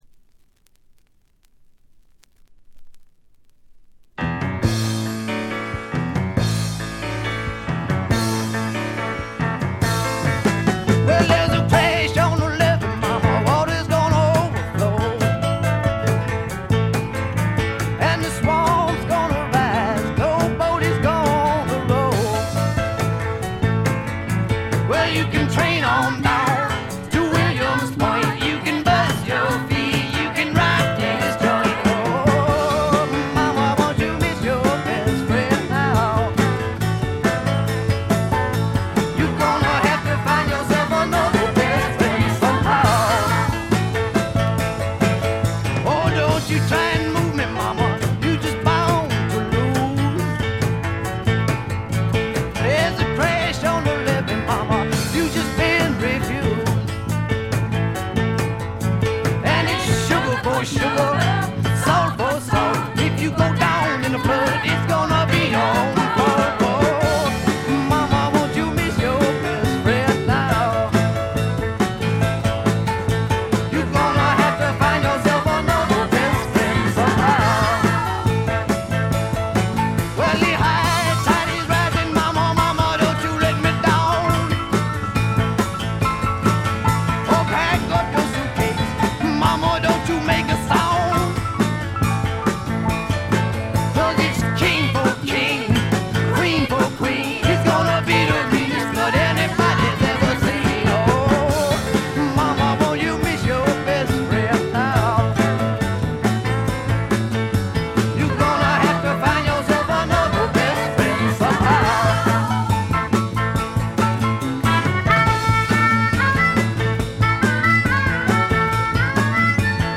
バックグラウンドノイズ、チリプチ多め大きめです。
試聴曲は現品からの取り込み音源です。